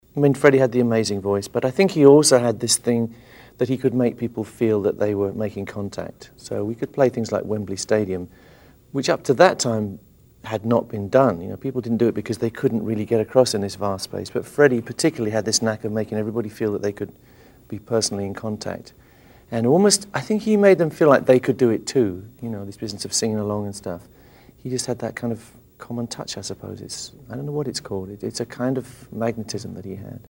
Dr. Brian May, CBE. Guitarist.
Brian megemlkezse /audi/